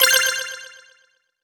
mixkit-extra-bonus-in-a-video-game-2045.wav